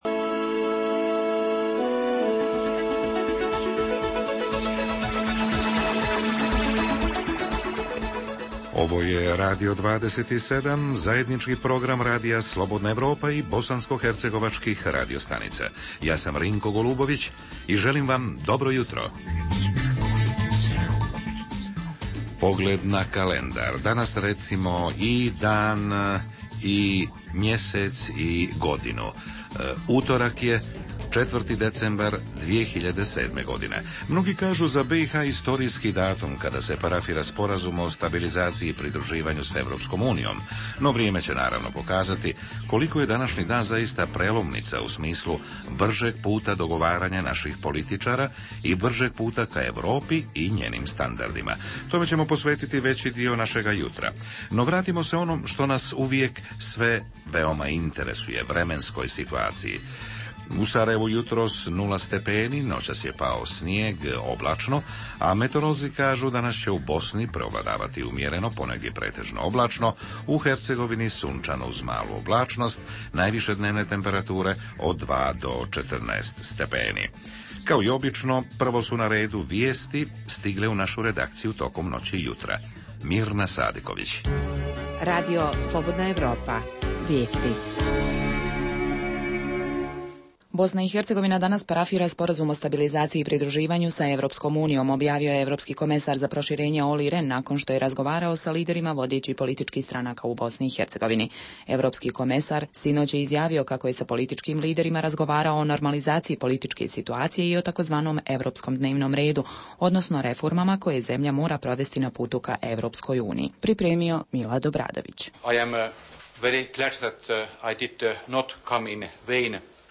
- Šta građani BiH očekuju nakon ovog parafiranja, da li će se političari početi brže dogovarati poštujući evropske principe, koliko godina će trebati BiH da postane punopravan člnn EU..., samo su neka pitanja koja su reporteri Radija 27 postavili građanima Sarajeva, Doboja, Travnika i Prijedora.- I gradovi su počeli da se prodaju preko interneta.